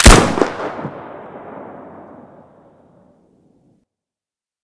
mp153_shoot_3.ogg